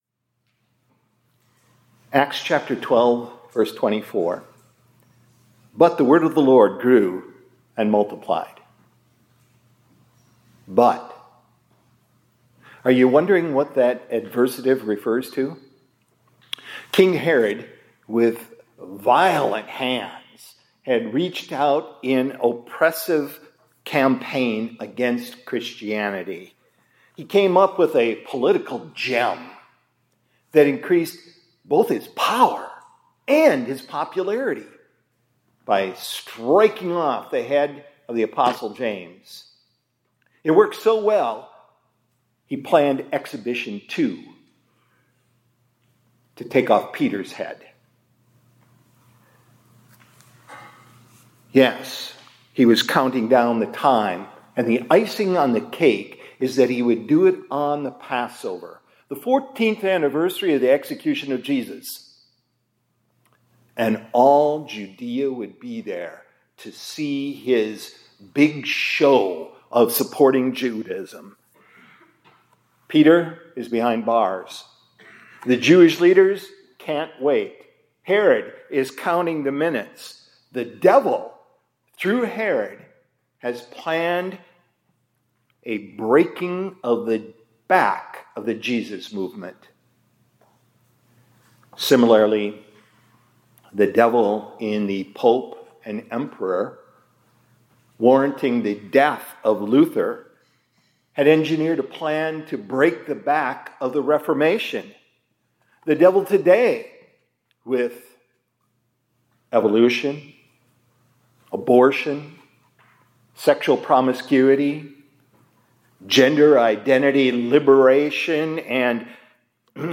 2025-10-30 ILC Chapel — But the Word of the Lord Grew